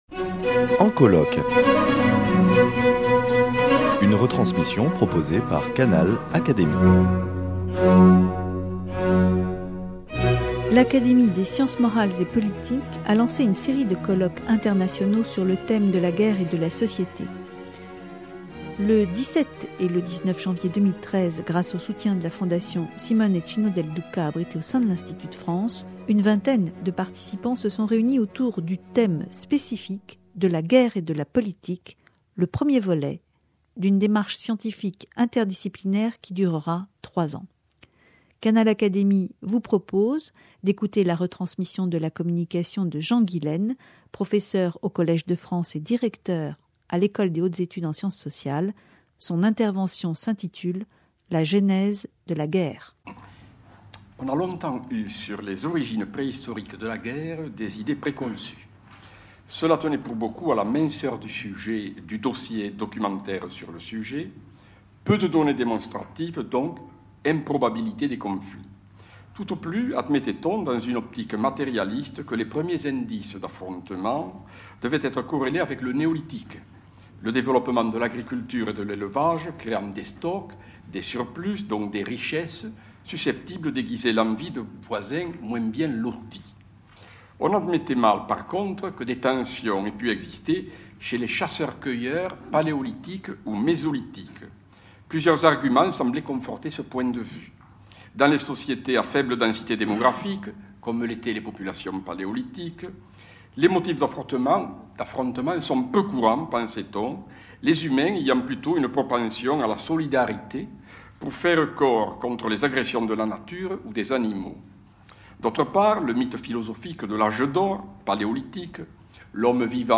L’Académie des sciences morales et politiques a organisé un colloque international sur le thème « Guerre et politique ». Parmi les intervenants, Jean Guilaine qui a occupé au Collège de France la chaire de Civilisations de l’Europe au Néolithique et à l’Âge du Bronze (1995-2007), interroge les origines de la guerre.